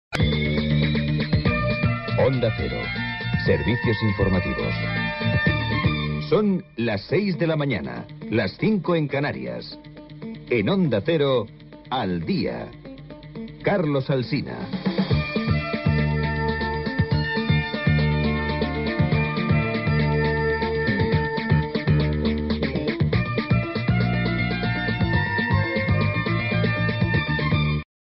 Programa presentat per Carlos Alsina.